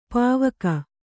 ♪ pāvaka